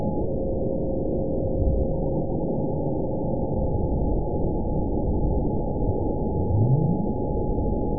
event 917802 date 04/16/23 time 23:45:08 GMT (2 years, 1 month ago) score 9.42 location TSS-AB04 detected by nrw target species NRW annotations +NRW Spectrogram: Frequency (kHz) vs. Time (s) audio not available .wav